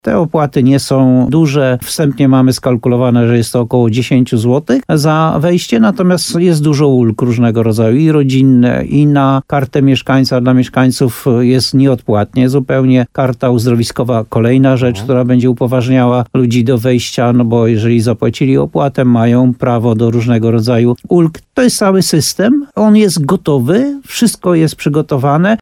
Jak przekonuje burmistrz Muszyny Jan Golba, przygotowane jest już rozwiązanie dotyczące opłat.